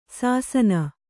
♪ sāsana